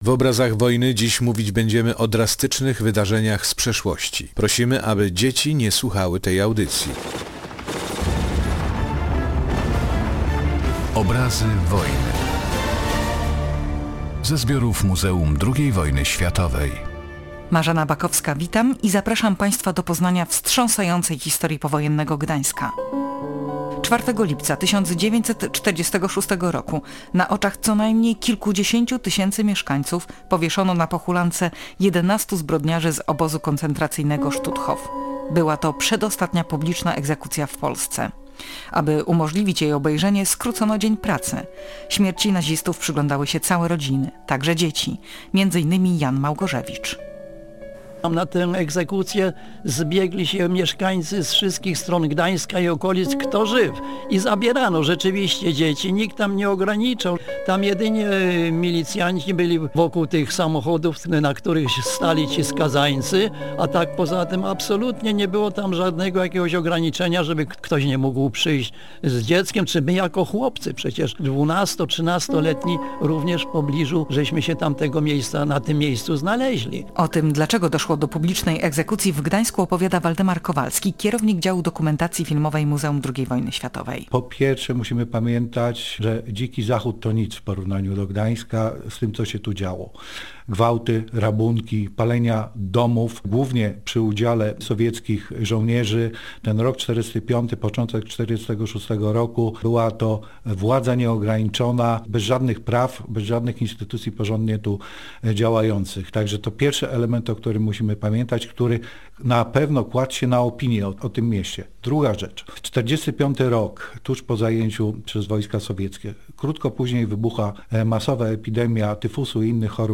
Głos zabiera świadek egzekucji
Wykorzystano także wypowiedzi ze zbiorów muzeum